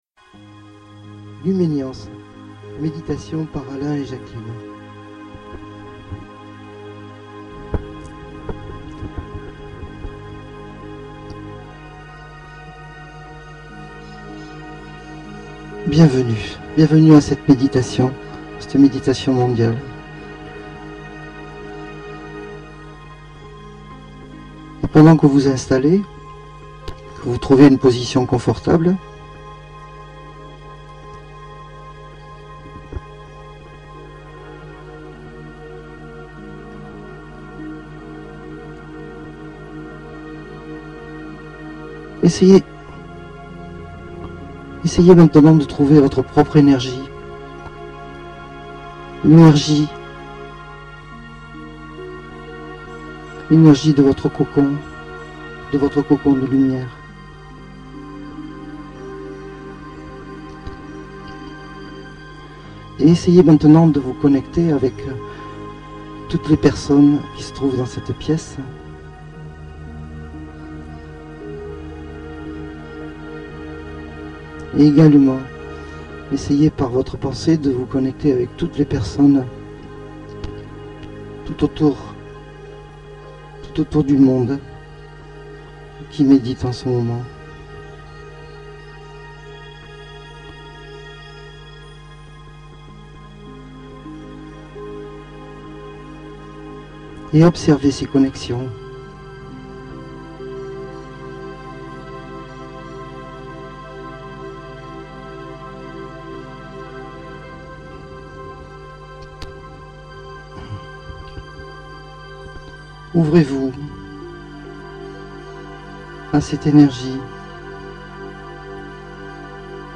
MEDITATION pour les Travailleurs de lumière, Servir l'Humanité